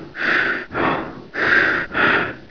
gasm_breath4.wav